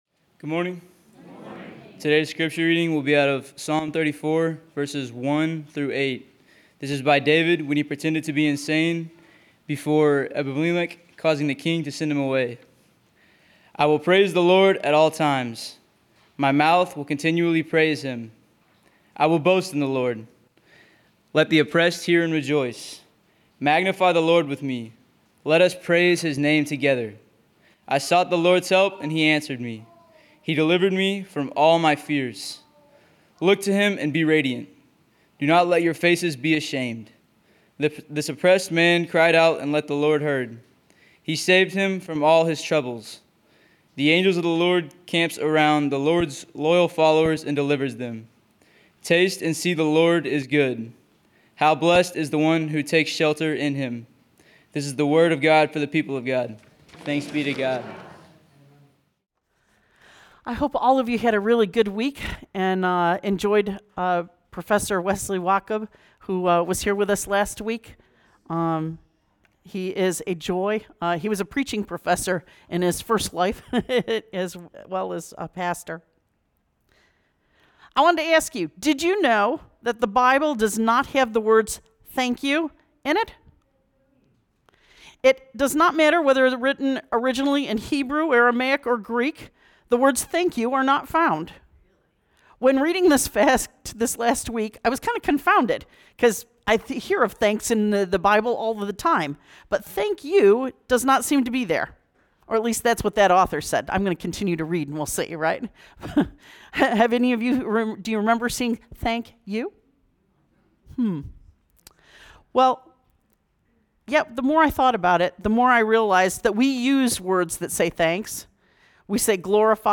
September 28, 2025 Sermon Audio